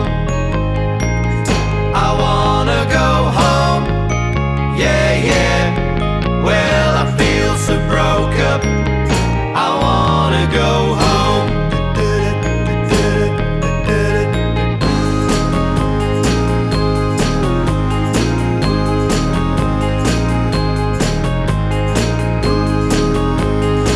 Vocal Only Ending Pop (1960s) 3:08 Buy £1.50